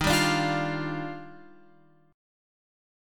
D# Major 9th